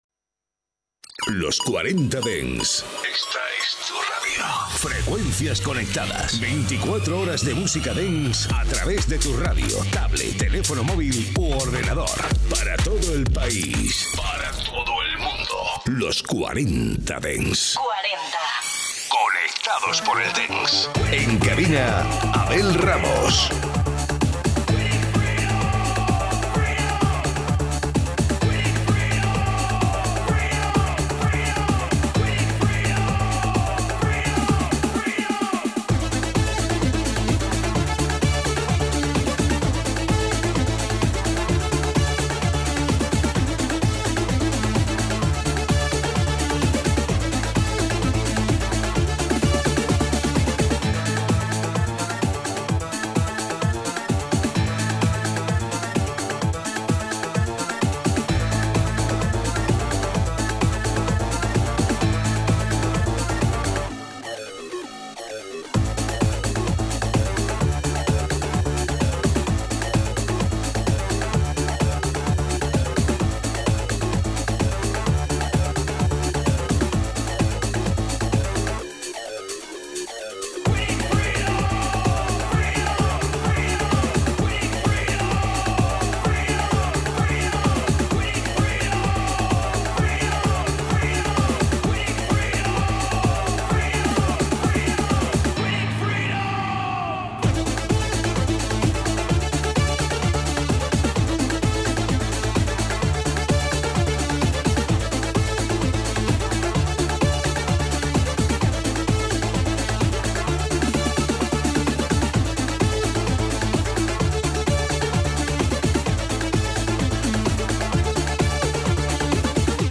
Escucha todos los temazos clásicos y míticos de la música dance de las últimas décadas